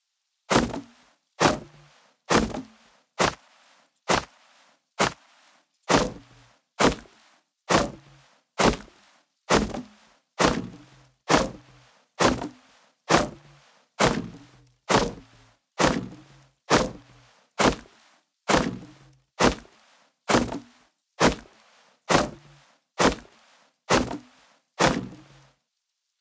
Stone Hatchet On Wood Wall